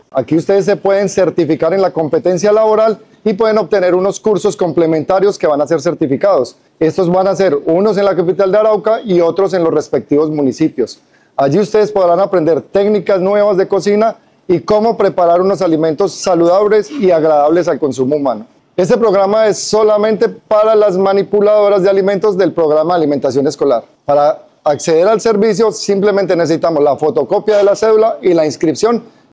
por Instructor SENA
Instructor-sena.mp3